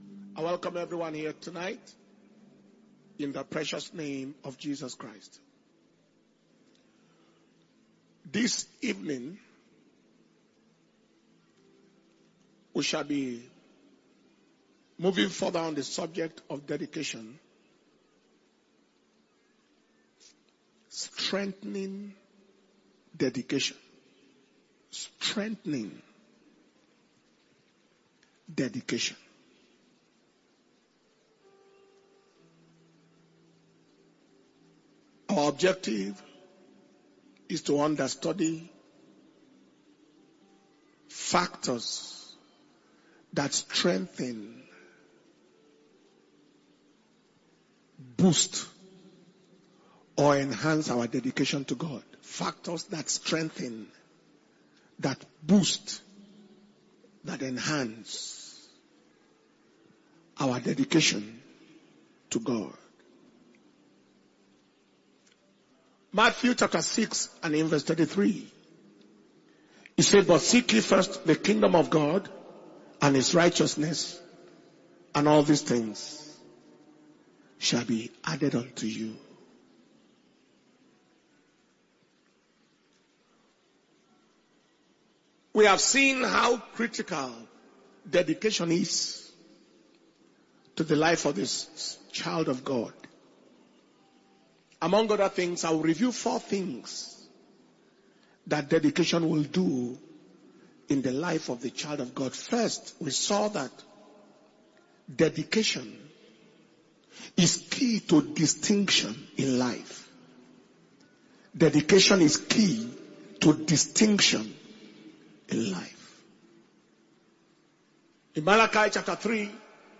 Power Communion Service/2024 Above Only 21 Days Fasting And Prayer